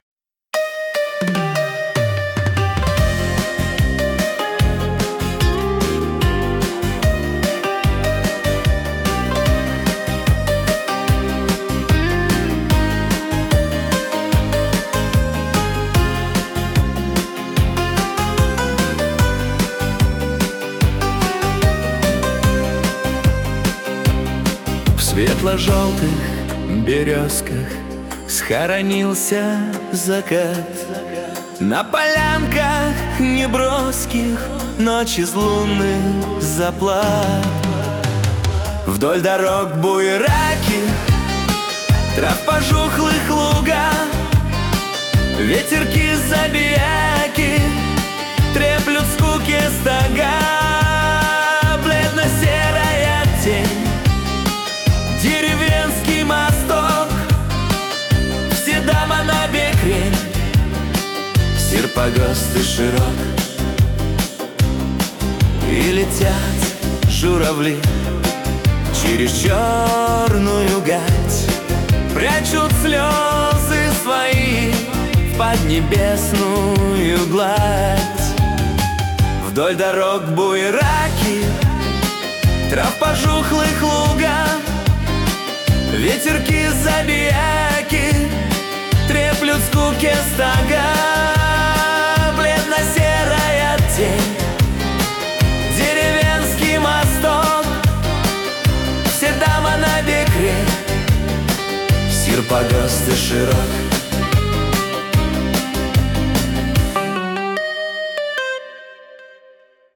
Видеопесню В СВЕТЛО-ЖЁЛТЫХ БЕРЁЗКАХ...1с смотреть, слушать, песня сгенерирована в нейросети: